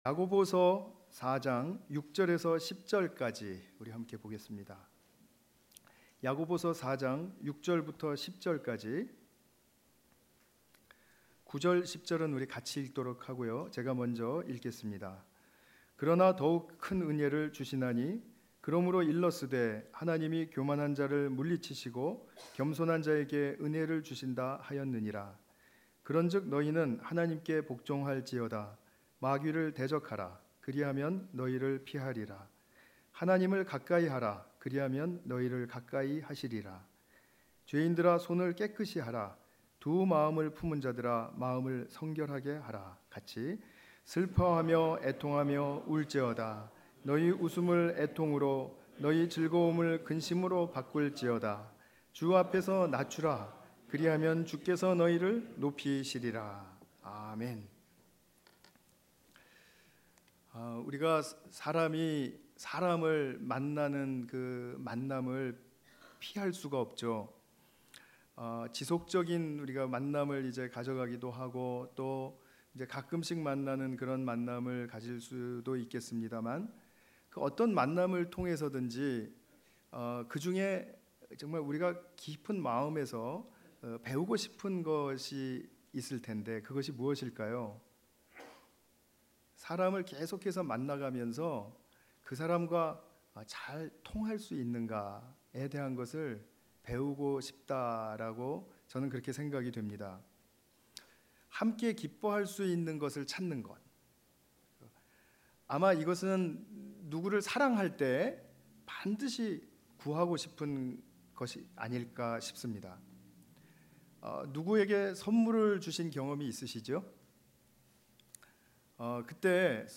관련 Tagged with 주일설교 Audio (MP3) 59 MB PDF 292 KB 이전 야고보서 (15) - 그리하면 더욱 큰 은혜를 주시는 하나님 다음 야고보서 (17) - 판단의 함정 0 댓글 댓글 추가 취소 댓글을 달기 위해서는 로그인 해야합니다.